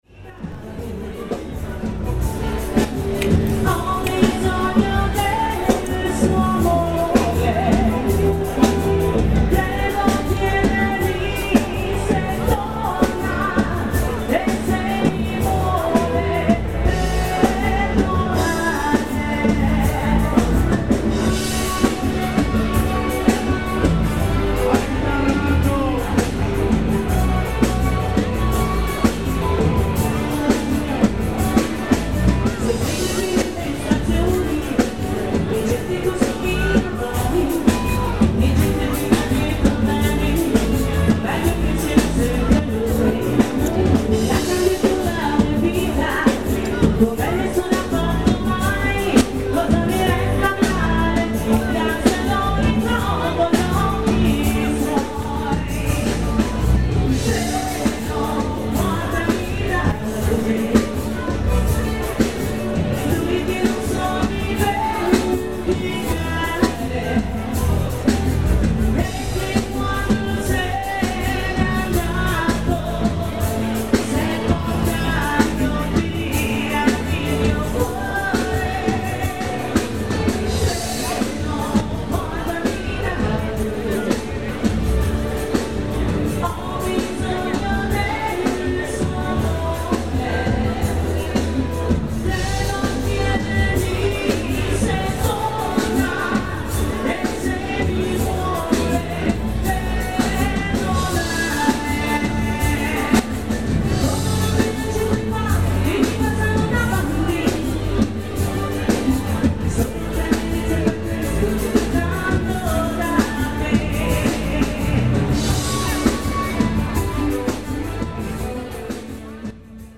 The annual San Rocco fair brings thousands of people in Barga to buy things in the markets and as evening falls they all congregate in the main square in Barga Giardino to listen to the music and to dance.